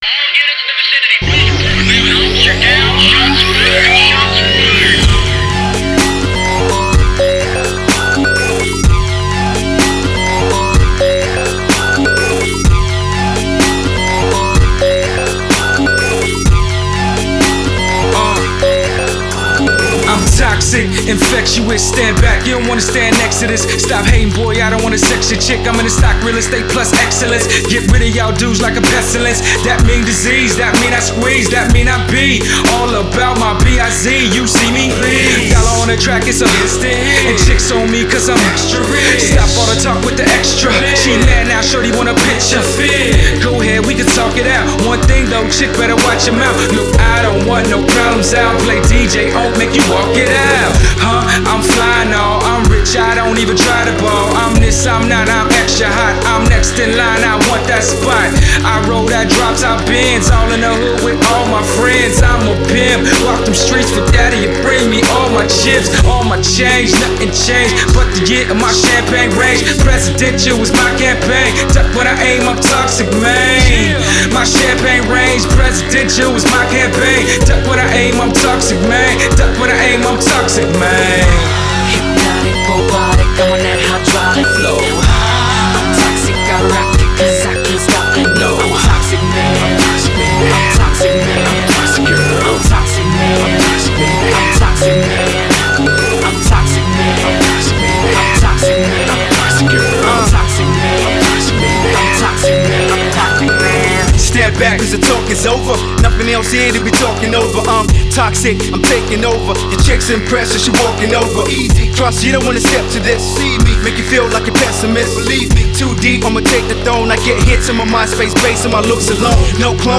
R&B, Hip Hop, Funk, Soul and Rock
talk-box skills that only get better by the song